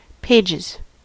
Ääntäminen
US
IPA : /ˈpeɪ.dʒɪz/